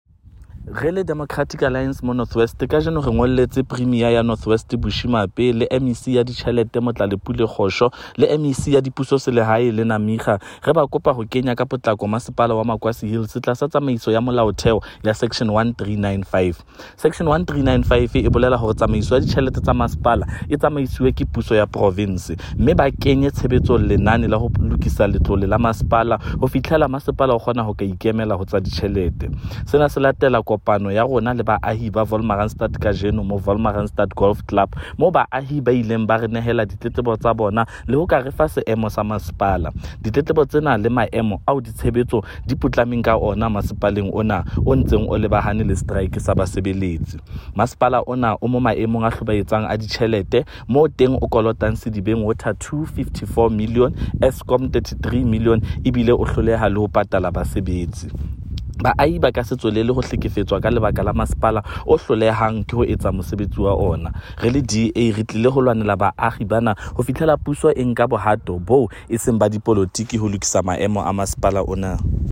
Sesotho by Freddy Sonakile MPL
Maquassi-Hills-Sesotho-Freddy-Sonakile.mp3